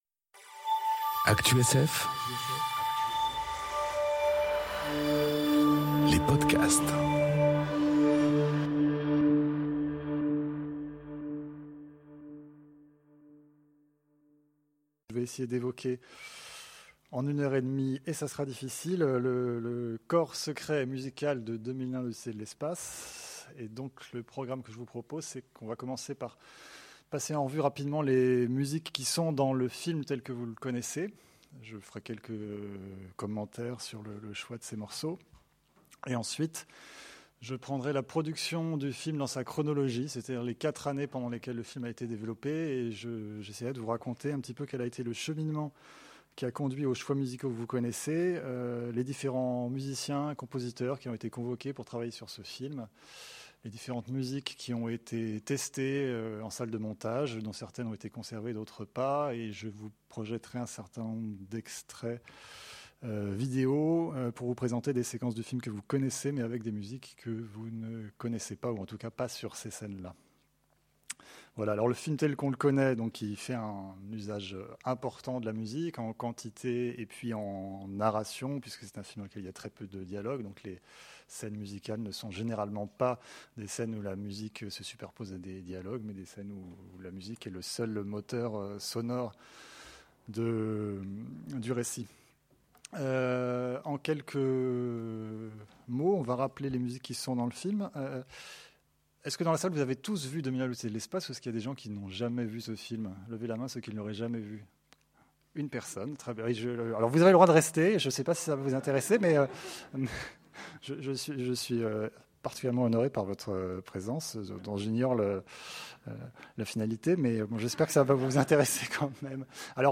Conférence Le corps secret et musical de 2001 enregistrée aux Utopiales 2018